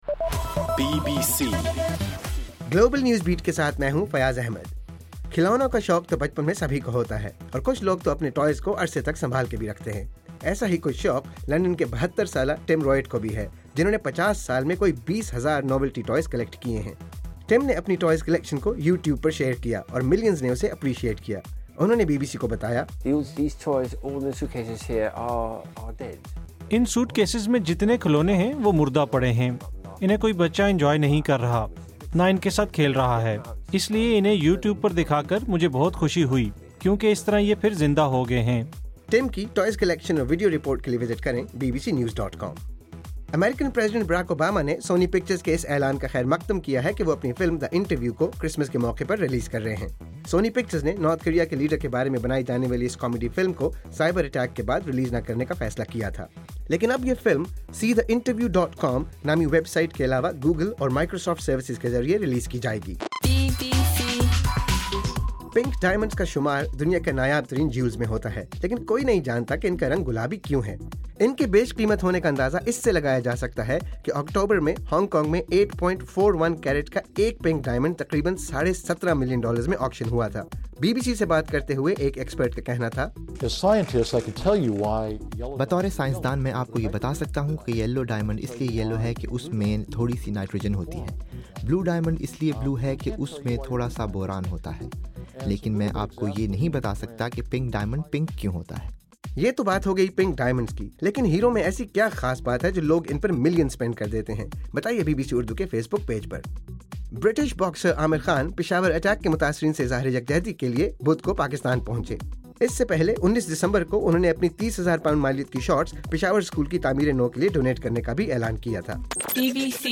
دسمبر 25: صبح 1 بجے کا گلوبل نیوز بیٹ بُلیٹن